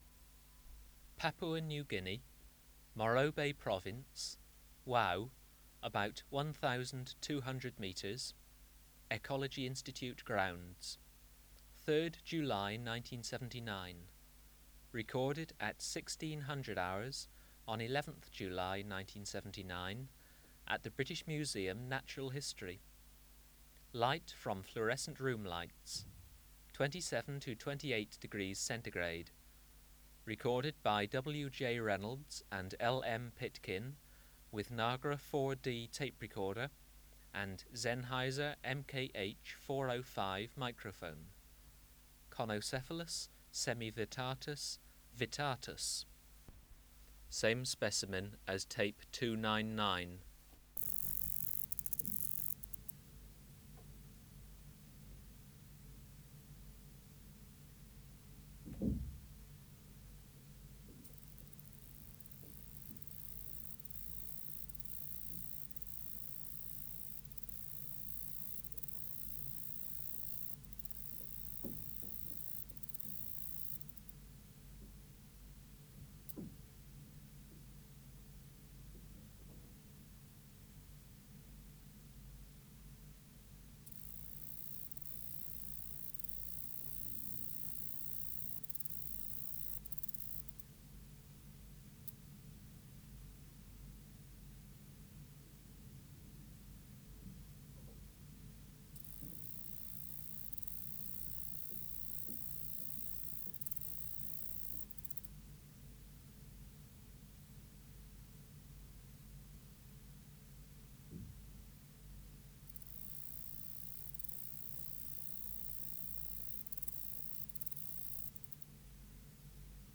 372:10 Conocephalus semivittatus vittatus(302) | BioAcoustica
Recording Location: BMNH Acoustic Laboratory
Reference Signal: 1 kHz for 10 s
Substrate/Cage: on egg carton
Microphone & Power Supply: Sennheiser MKH 405 Distance from Subject (cm): 20
Recorder: Kudelski Nagra IV D (-17dB at 50Hz)